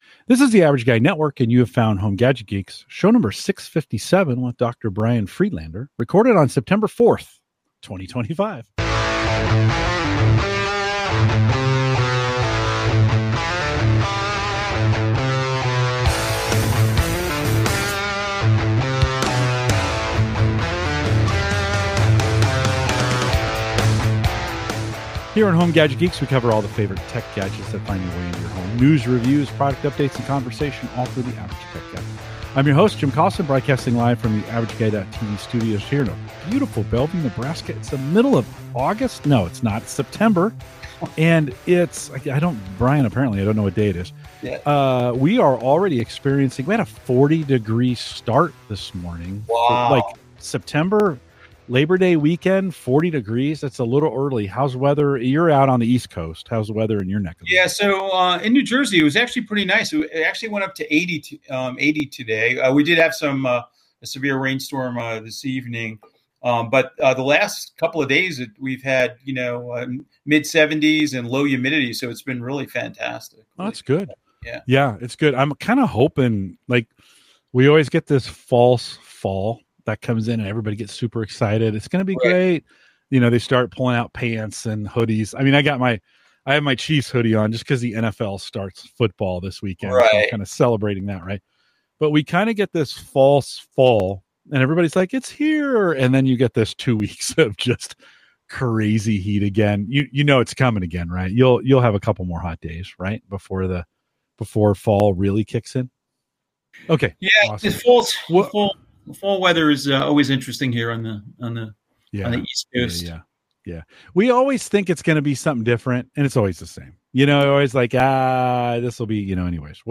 It was a fascinating conversation about where accessibility meets technology, and how sometimes the simplest solutions end up being the most powerful.